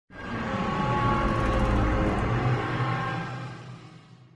repair2.ogg